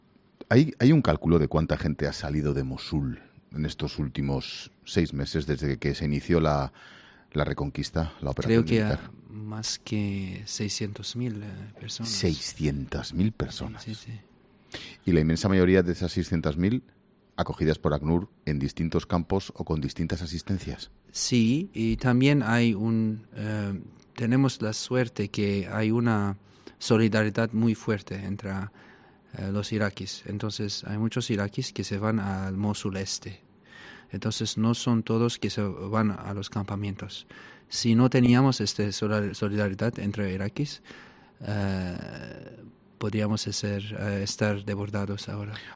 Este lunes, 'La Tarde' de Ángel Expósito se emite desde Mosul, ciudad iraquí que sufre la difícil situación de los refugiados que huyen del Estado Islámico.